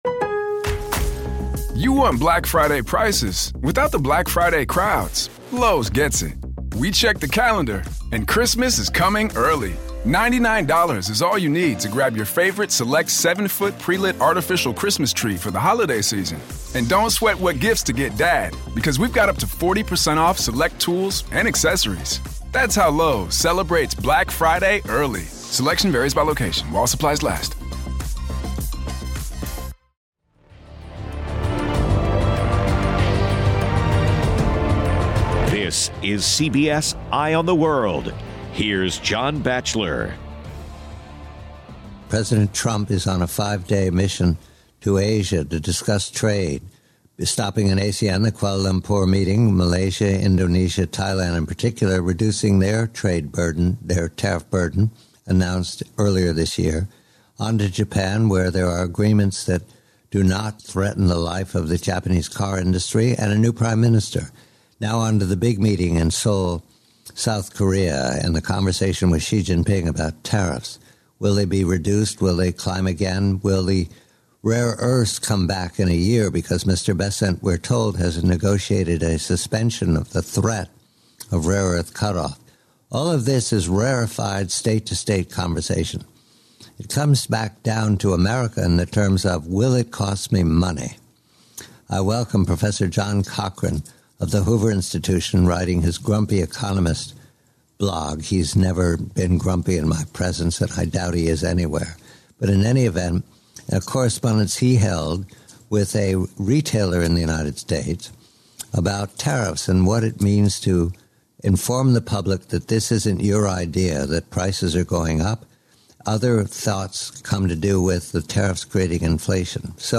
Guest: Professor John Cochrane